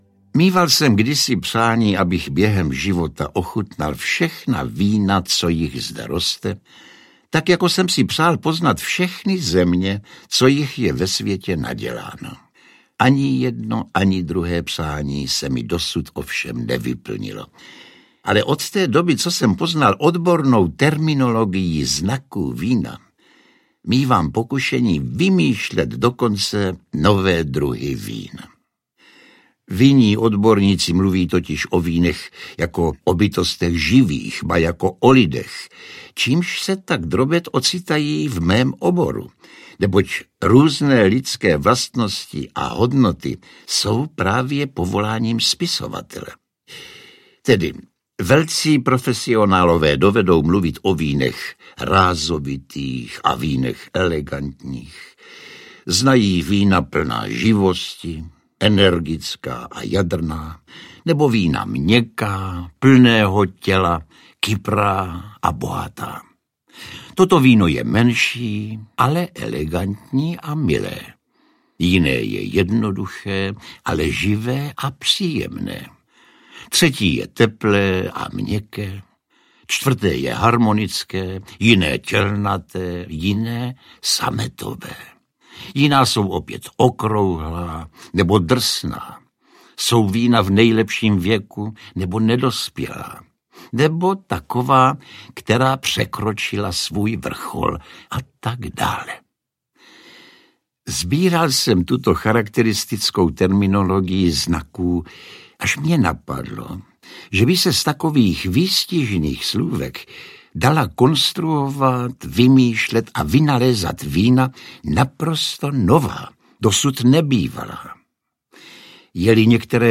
O víně audiokniha
Ukázka z knihy
Audiokniha, která potěší nejen milovníky vína!Výběr literárních textů na téma víno od známých českých autorů interpretují pánové Josef Somr, Miroslav Táborský, Rudolf Křesťan a Ivan Kraus. Vinařské povídky a fejetony z pera Karla Čapka, Miroslava Horníčka, Adolfa Hoffmeistera, Rudolfa Křesťana, Ivana Krause a Ladislava Špačka.
• InterpretJosef Somr, Miroslav Táborský, Rudolf Křesťan, Ivan Kraus